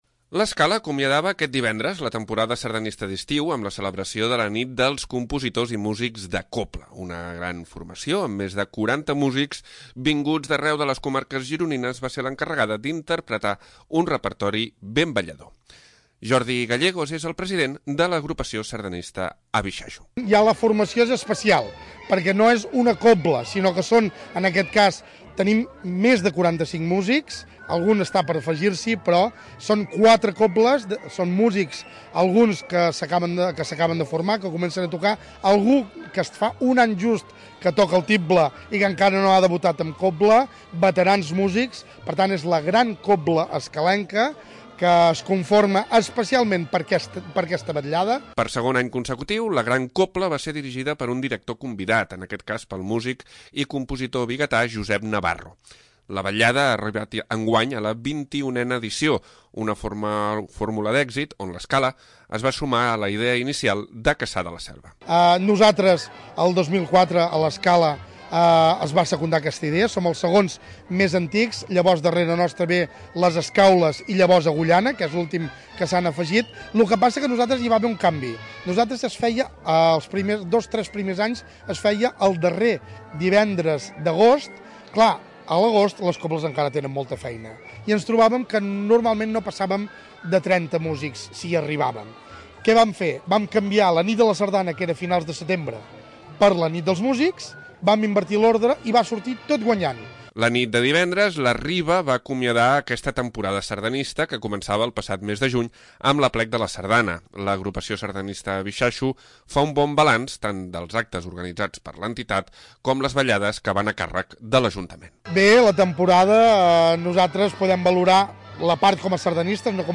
L'Escala acomiadava aquest divendres la temporada sardanista d'estiu amb la celebració de la Nit de Compositors i Músics de Cobla. Una gran formació, amb més de 40 músics vinguts d'arreu de les comarques gironines va ser l'encarregada d'interpretar un repertori ben ballador.